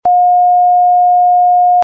sound  healing, vibration, brain wave frequency, cimatic  therapy
716 Hz (demo